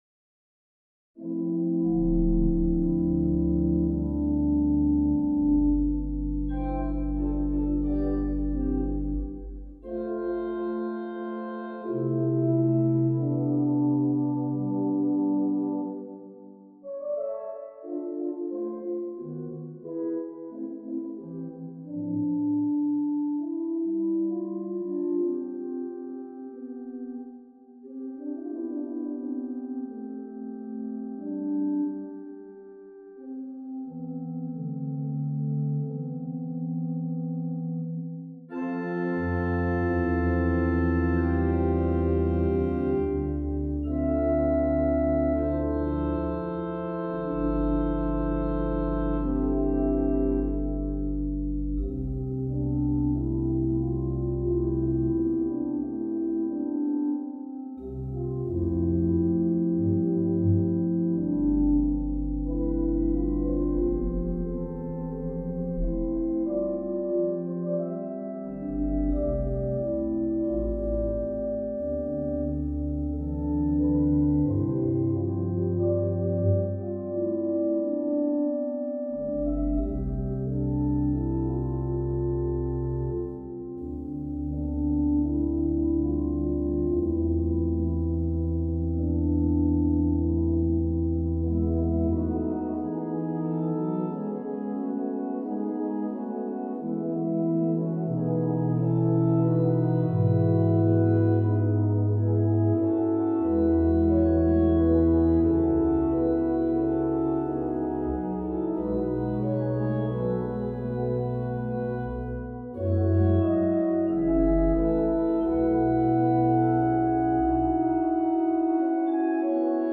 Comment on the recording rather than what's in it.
3 pages, circa 5' 30" an MP3 demo is here: